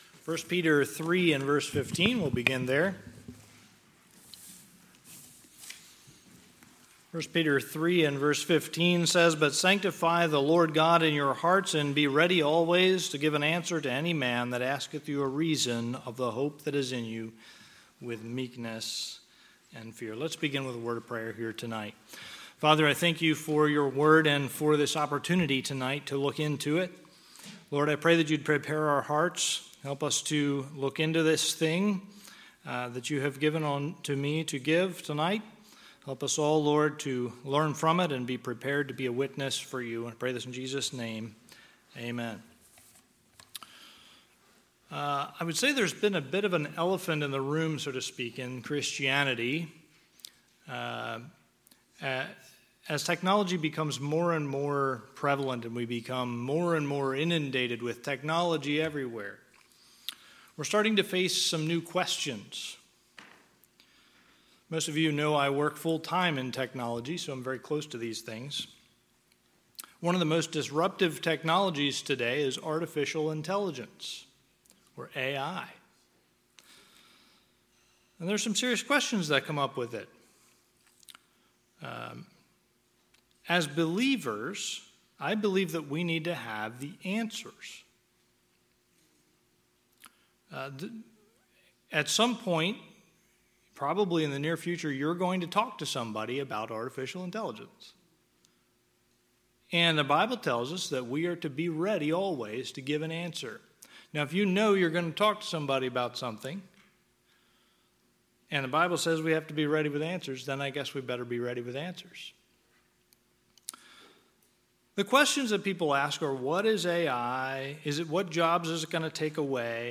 Sunday, November 2, 2025 – Sunday PM